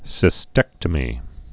(sĭ-stĕktə-mē)